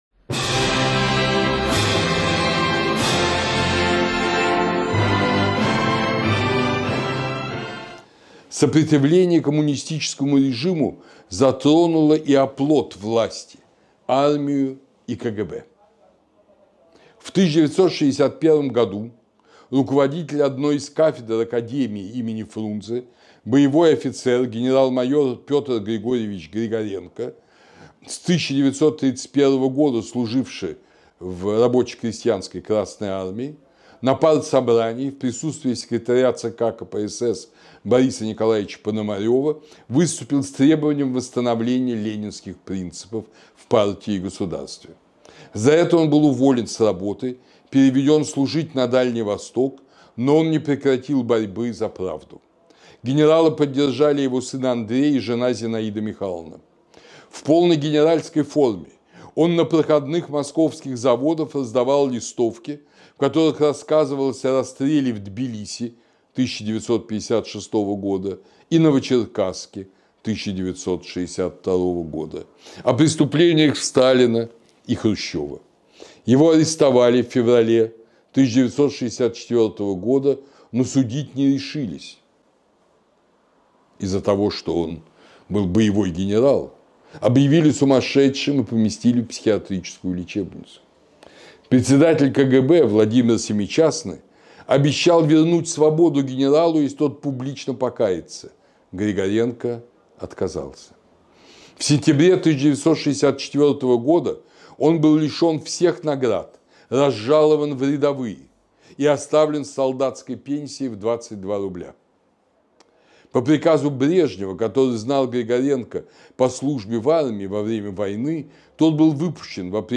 В седьмой беседе по новейшей истории России рассказывается о забытых страницах сопротивления режиму в Вооруженных силах и КГБ.